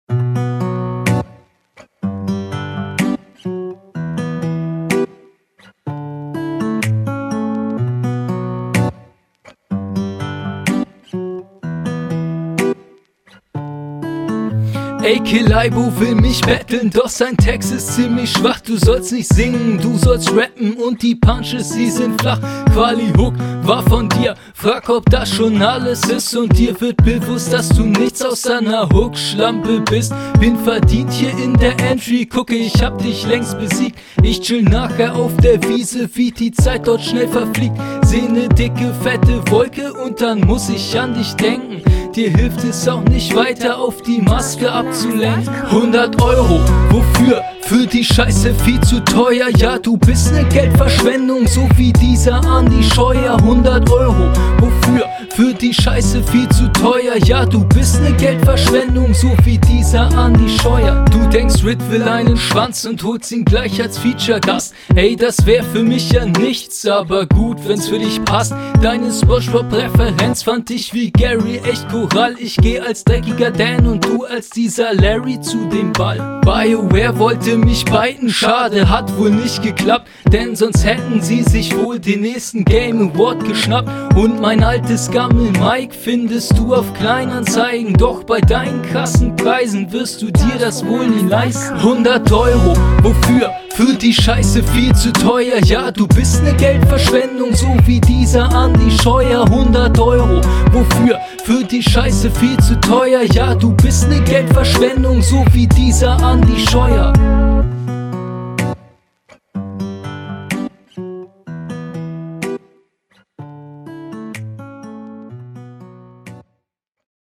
Du gibst die viel Mühe um verständlich zu rappen, das hört man raus.